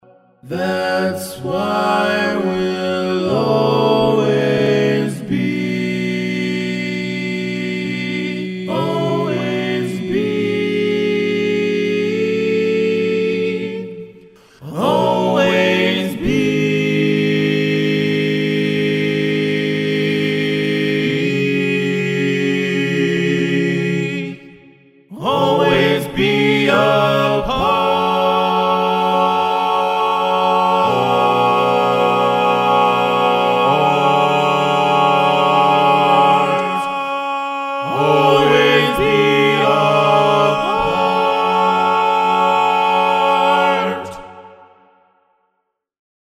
Key written in: F# Minor
How many parts: 4
Type: Barbershop
All Parts mix:
Learning tracks sung by